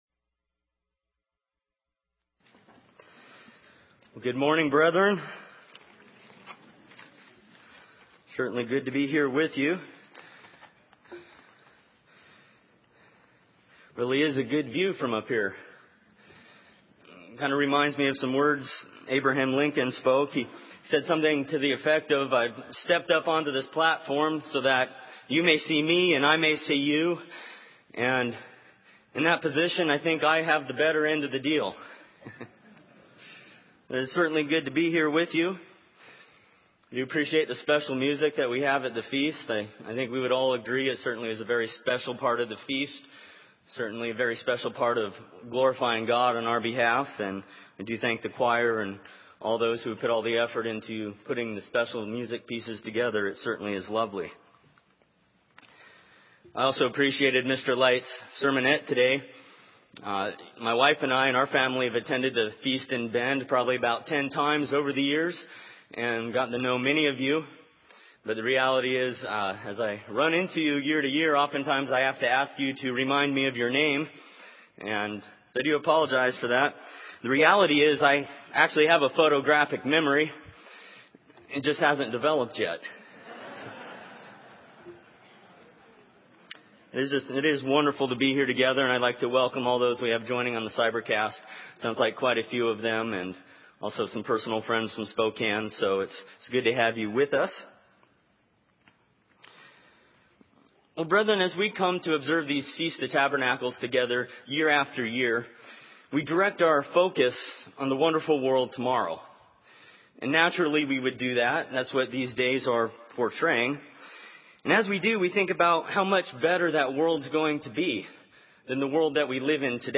This sermon was given at the Bend, Oregon 2011 Feast site.